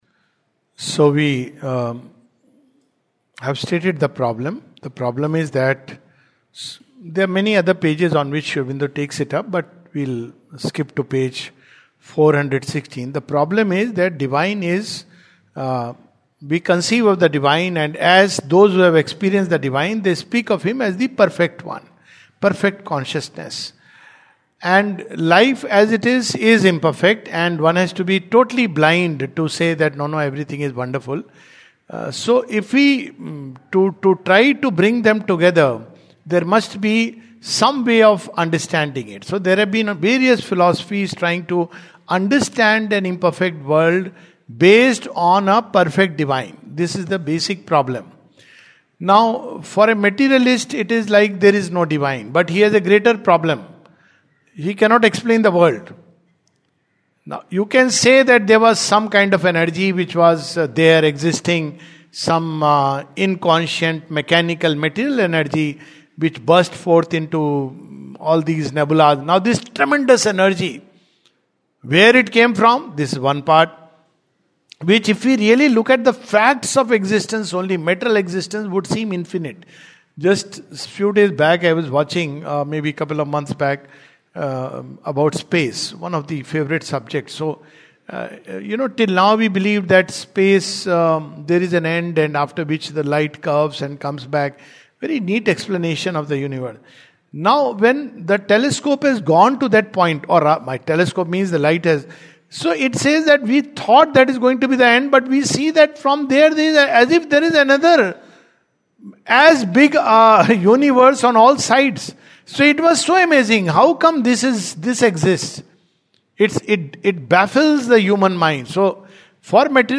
The Life Divine, 25th February 2026, Session # 06-08 at Sri Aurobindo Society, Pondicherry - 605002, India. The Divine and the Undivine (Part 4).